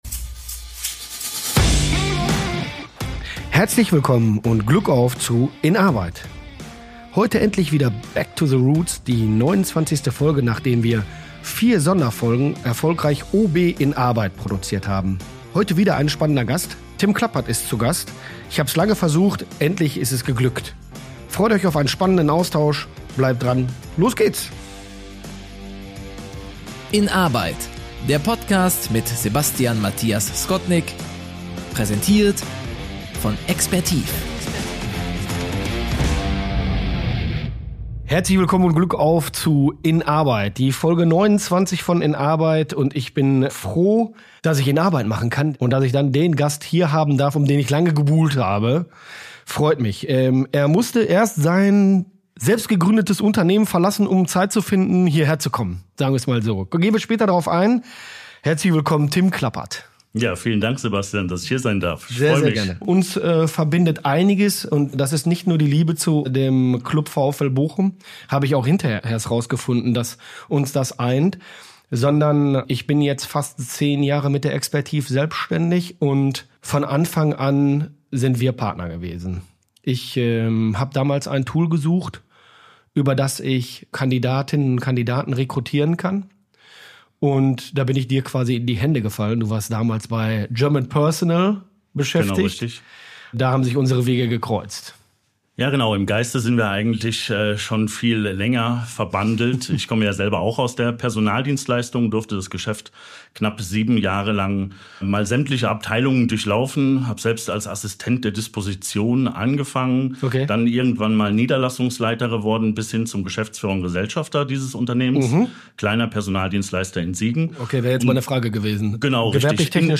Ein Gespräch über Risiko und Mut, über die Balance zwischen Erfolg und Freiheit, über technologische Innovationen und den unersetzlichen menschlichen Faktor im digitalen Zeitalter.